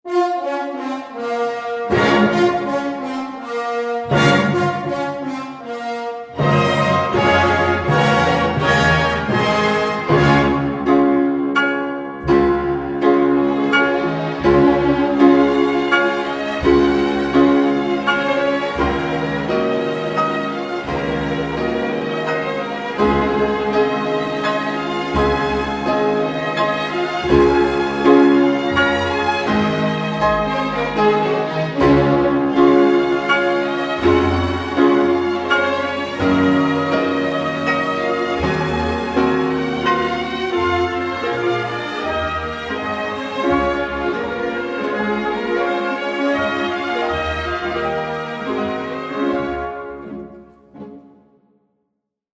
Tonos Música Clásica